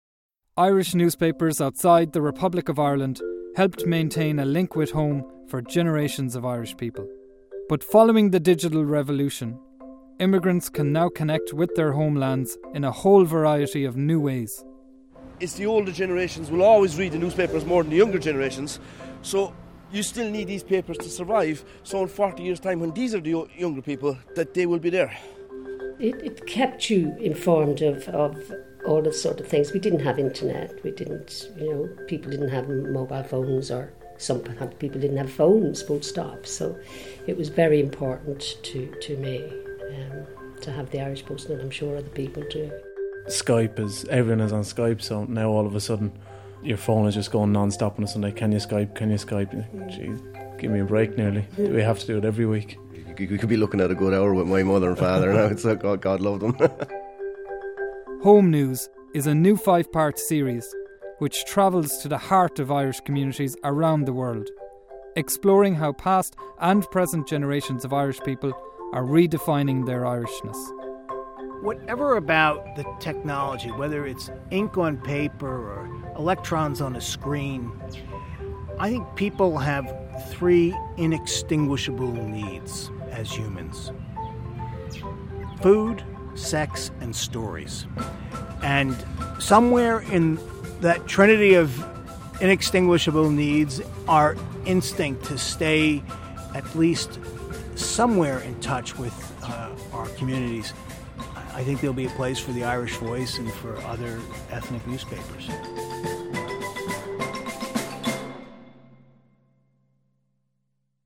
Home News is a new 5-part RTE Radio 1 series, supported by the BAI, which looks at several Irish newspapers outside the Republic of Ireland, and their readers. It features the Irish Post in London, Irish Voice in New York, Irish News in Belfast, Irish Echo in Sydney and others. In this clip we hear emigrants in London and Sydney, as well as Pulitzer Prize winning New York Times Journalist Jim Dwyer.